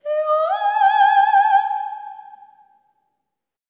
LEGATO 02 -L.wav